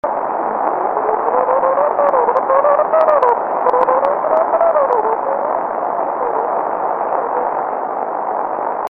Попробовал записать приемник на сотовый тлф (вместо микрофона гарнитуры подключен к выходному трансформатору приемника), пока не очень хорошо получилось, но для представления, как звучит комнатная антенна 14 см диаметром и регенератор с потреблением 10 мкА